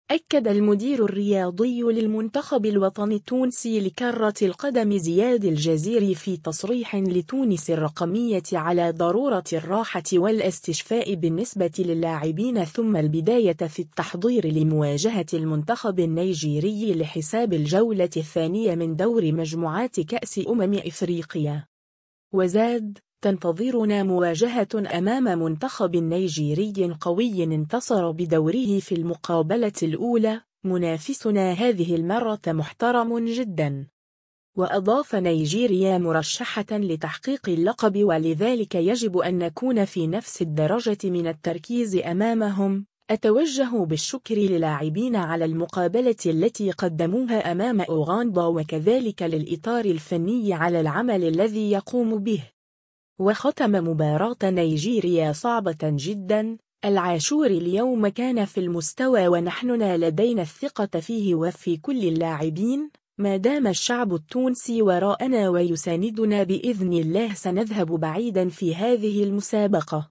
أكّد المدير الرياضي للمنتخب الوطني التونسي لكرة القدم زياد الجزيري في تصريح لتونس الرقمية على ضرورة الراحة و الإستشفاء بالنسبة للاعبين ثم البداية في التحضير لمواجهة المنتخب النيجيري لحساب الجولة الثانية من دور مجموعات كأس أمم إفريقيا.